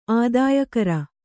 ♪ ādāyakara